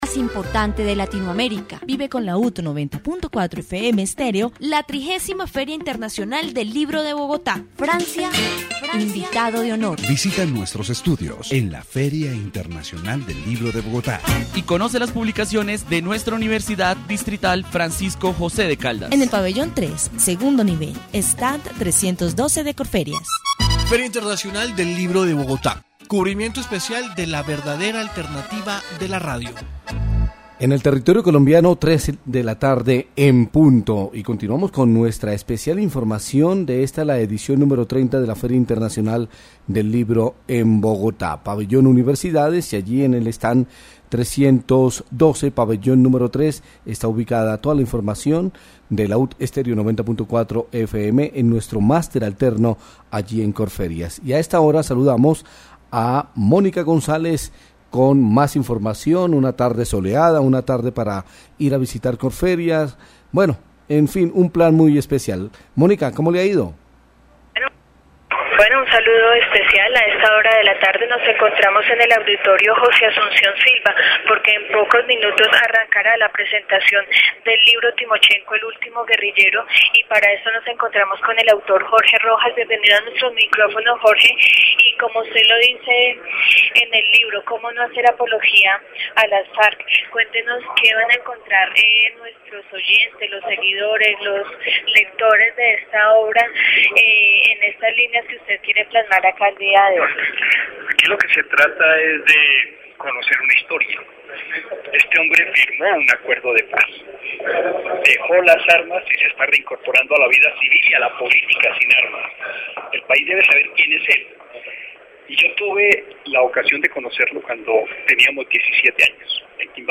dc.subject.lembProgramas de radio